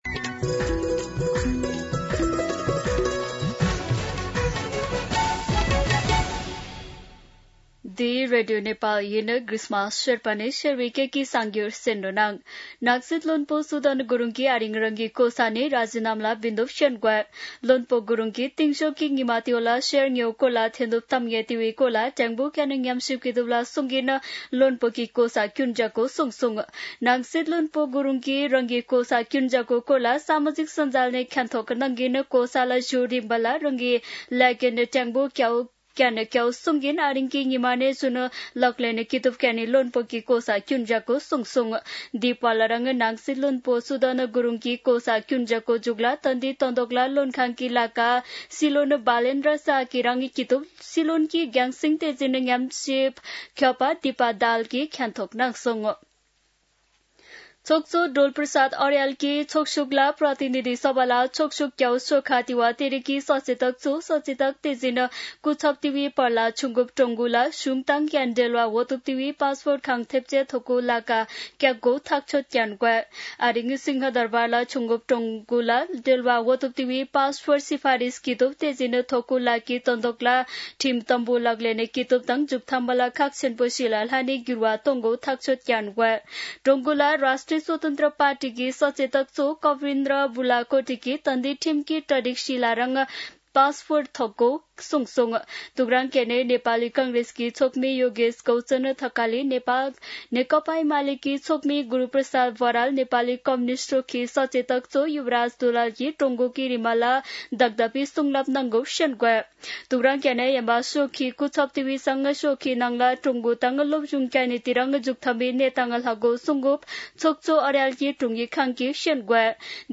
शेर्पा भाषाको समाचार : ९ वैशाख , २०८३
Sherpa-News-09.mp3